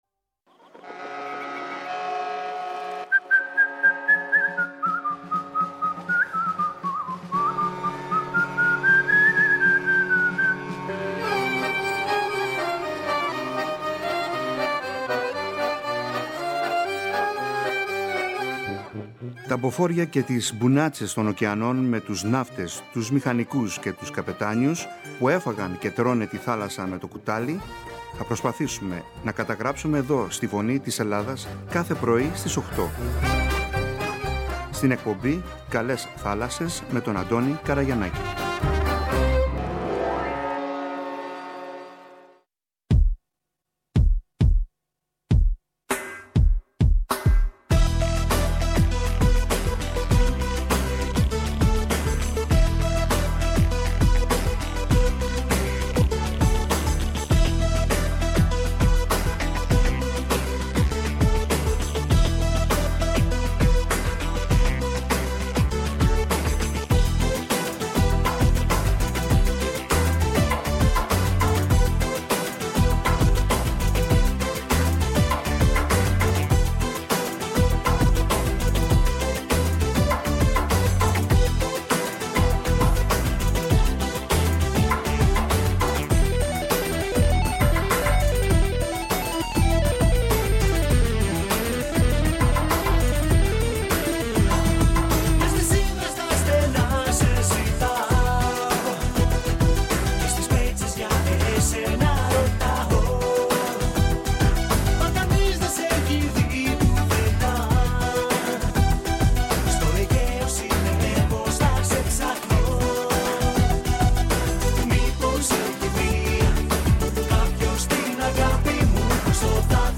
Οι άνθρωποι που μιλούν – φιλοξενούνται στην εκπομπή κυρίως οι παλιότεροι έζησαν απίστευτες ιστορίες παλεύοντας στα άγρια νερά όλου του κόσμου, ρισκάροντας κάθε μέρα να αποδώσουν τον ύστατο φόρο στη θάλασσα κι ότι έκαναν το έκαναν με κόπους και έζησαν πολύ σκληρά χρόνια, μακριά από τις οικογένειές τους.